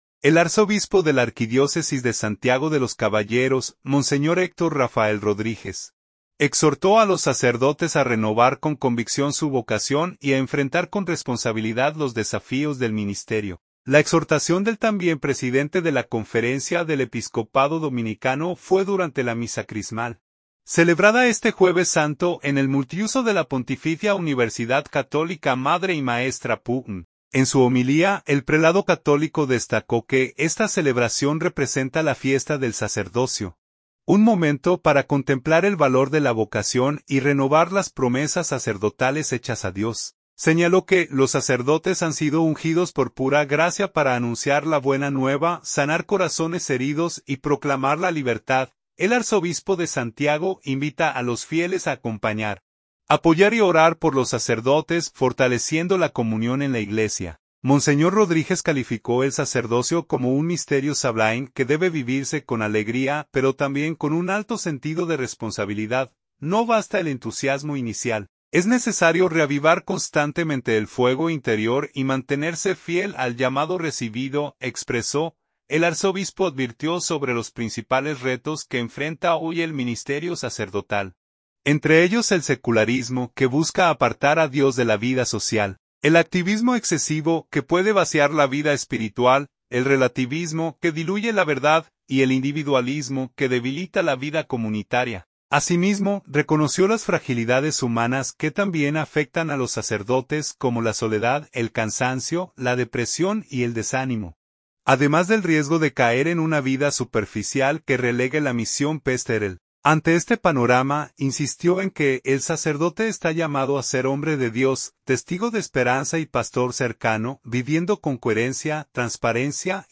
La exhortación del también presidente de la Conferencia del Episcopado Dominicano fue durante la Misa Crismal, celebrada este Jueves Santo en el multiuso de la Pontificia Universidad Católica Madre y Maestra (Pucmm).